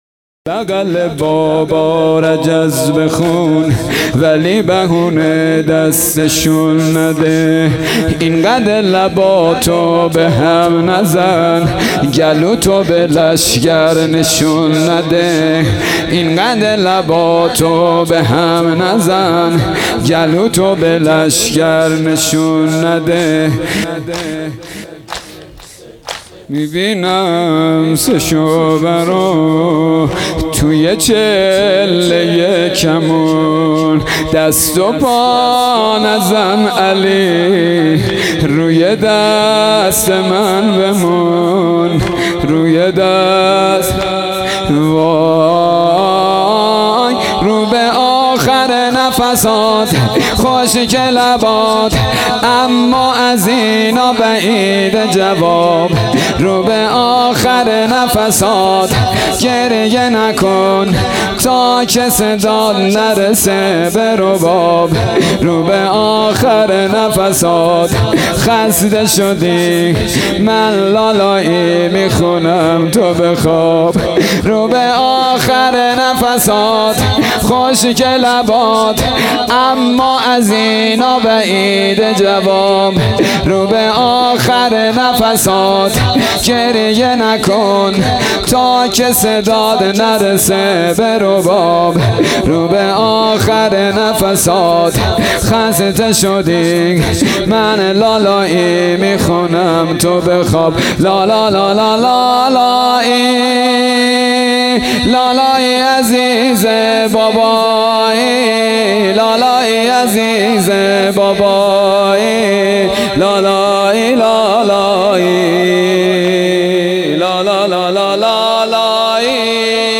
شب هفتم محرم الحرام 1443